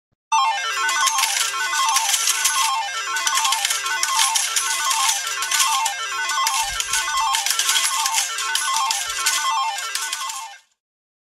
Casino Jackpot Sound Effect Free Download
Casino Jackpot